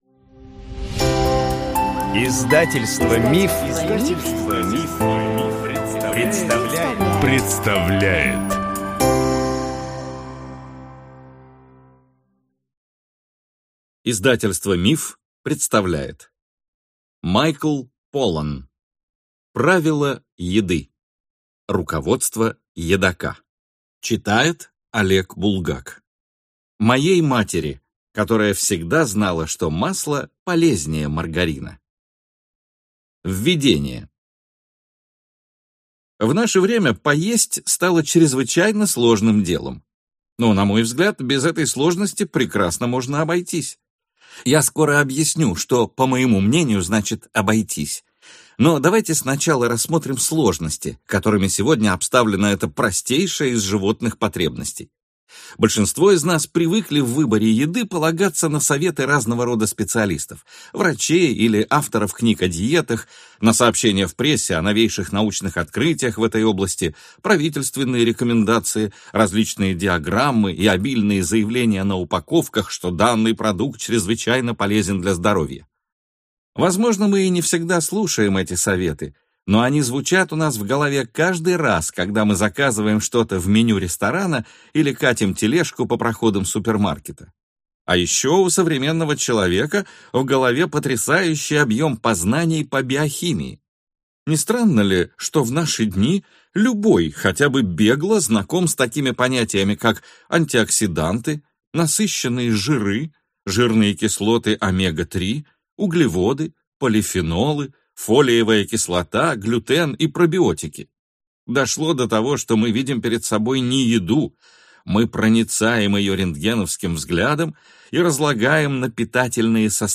Аудиокнига Правила еды. Руководство едока | Библиотека аудиокниг